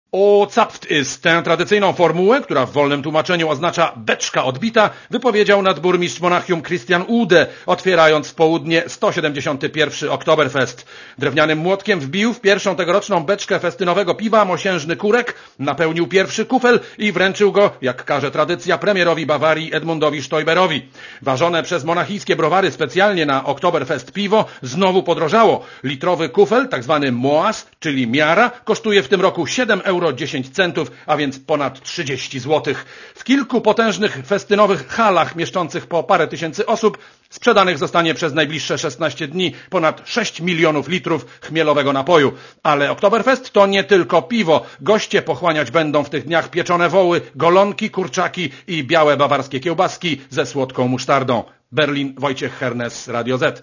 Korespondencja z Niemiec